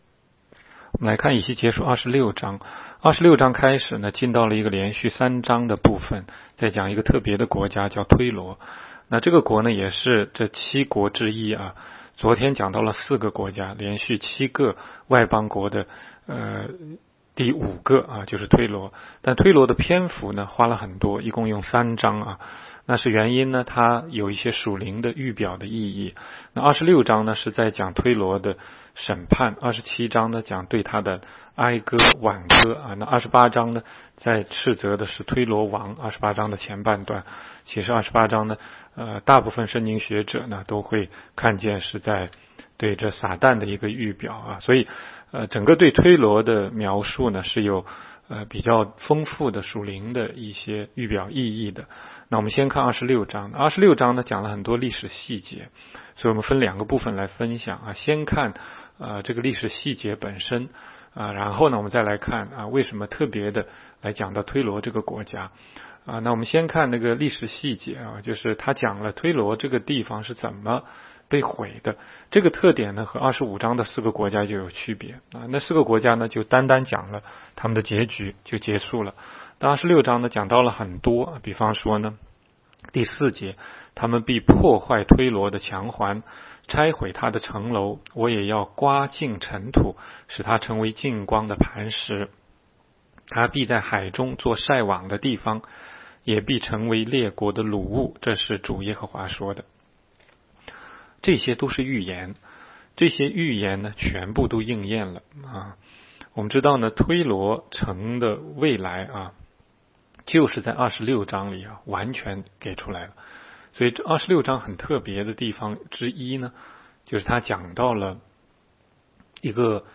16街讲道录音 - 每日读经 -《以西结书》26章